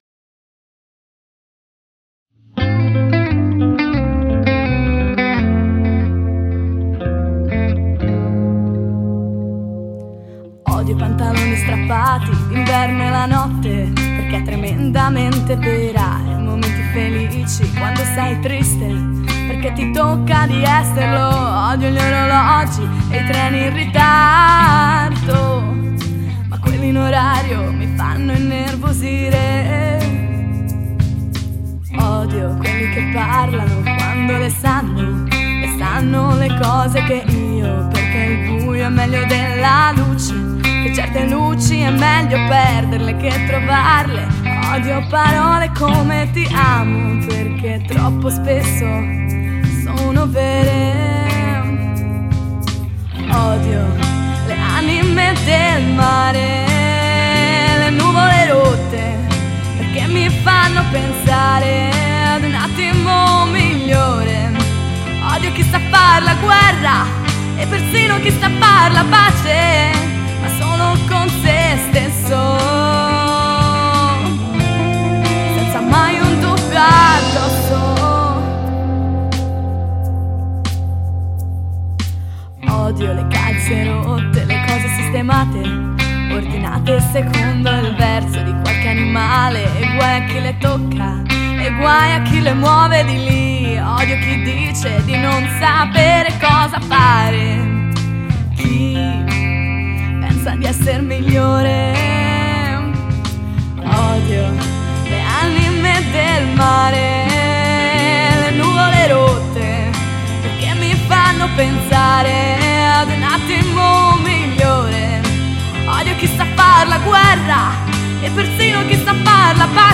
Voce
Chitarra El.
Basso El.